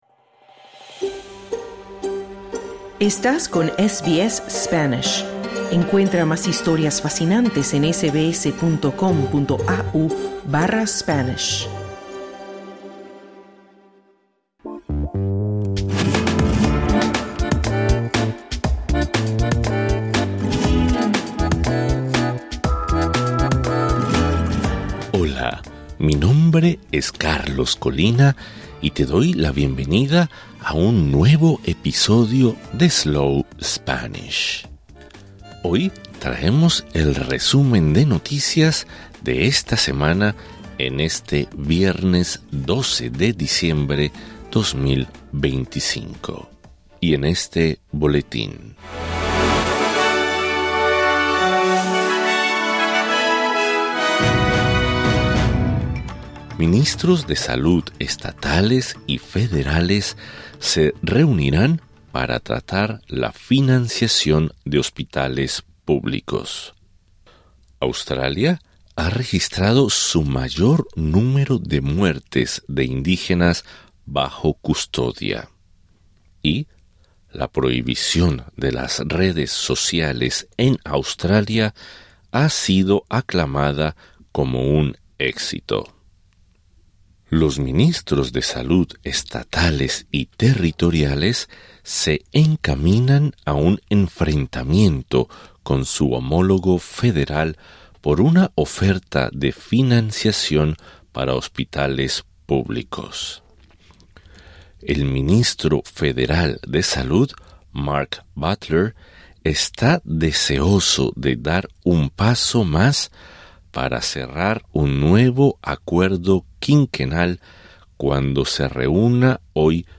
Welcome to SBS Slow Spanish, a new podcast designed in Australia specifically for those interested in learning the second most spoken language in the world. This is our weekly news flash in Spanish for December 12, 2025.